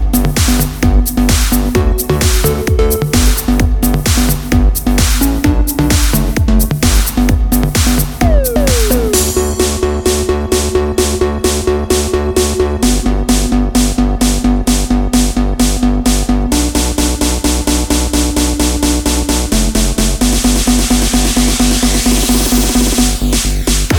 no Backing Vocals Dance 3:59 Buy £1.50